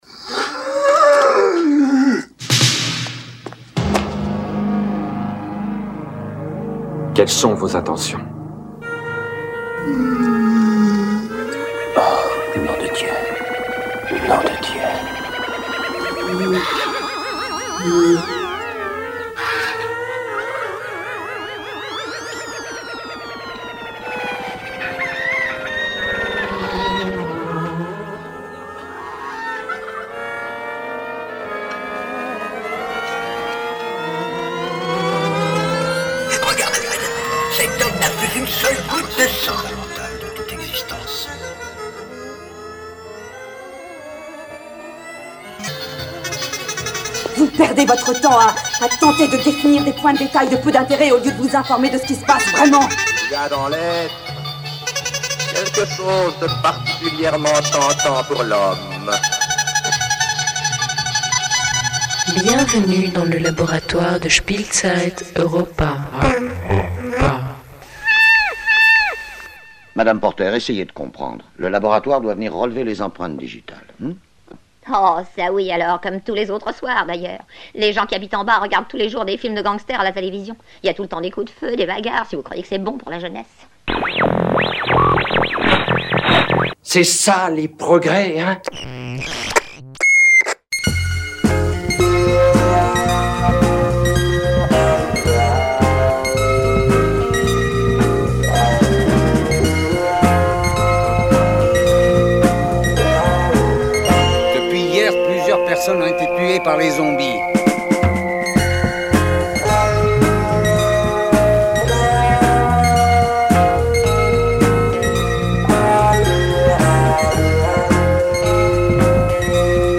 Blog,Mix,Mp3,collage sonore